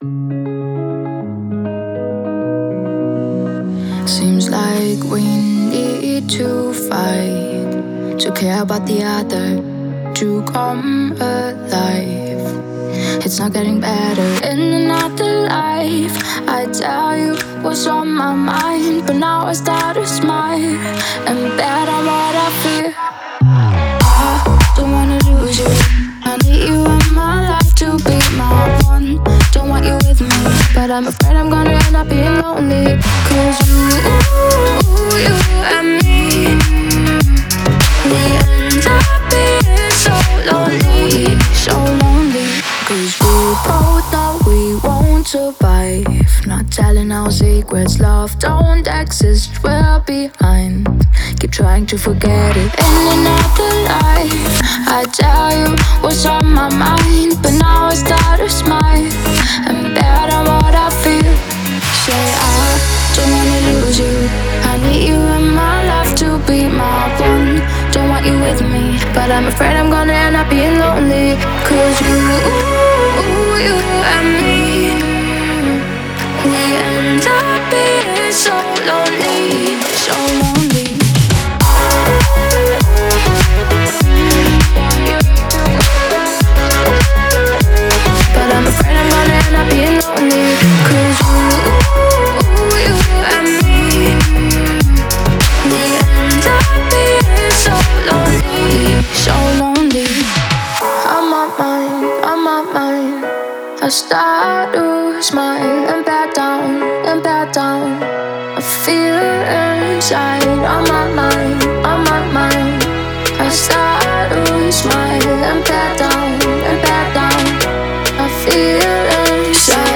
электронная поп-песня